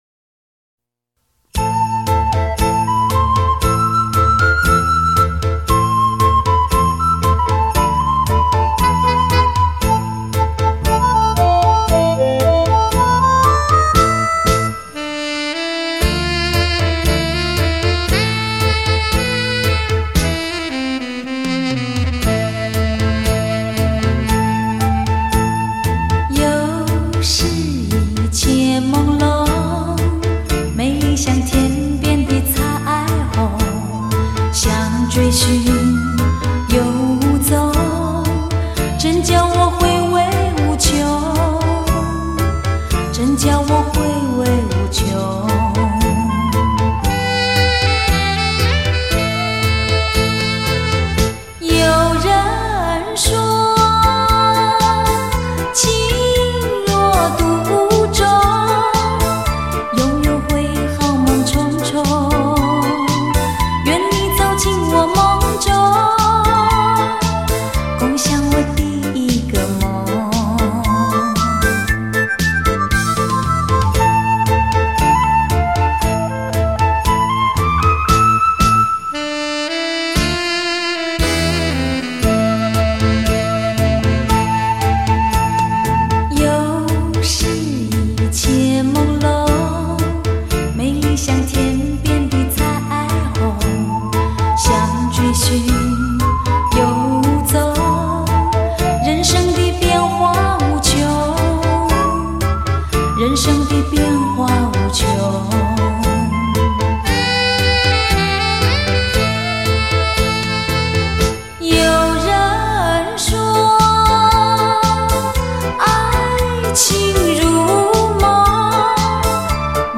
山地舞步
REM GENRE Dance